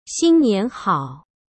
HERE’S HOW TO SAY THE CNY GREETINGS IN MANDARN
xīnnián hǎo